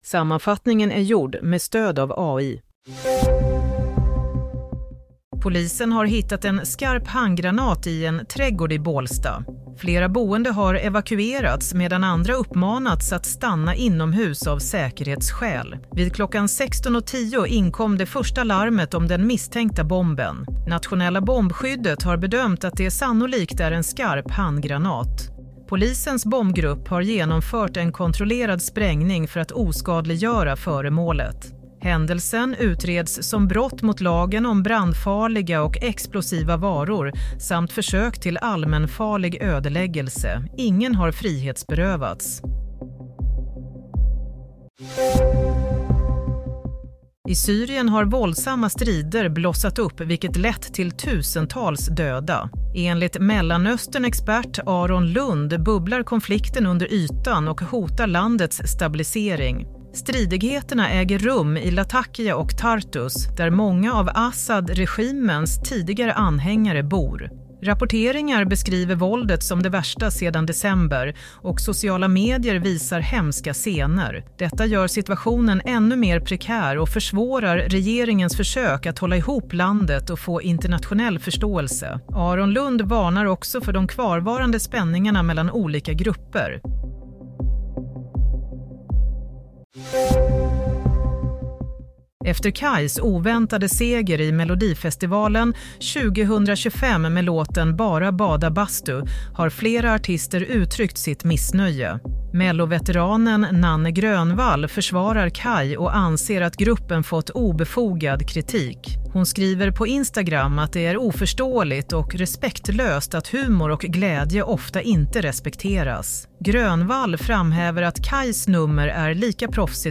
Nyhetssammanfattning – 9 mars 22:00